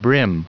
Prononciation du mot brim en anglais (fichier audio)
Prononciation du mot : brim